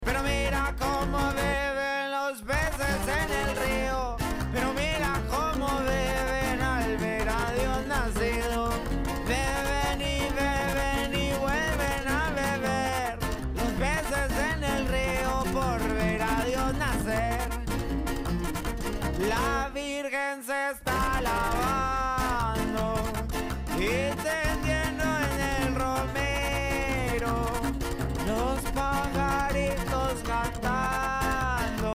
corridos navideños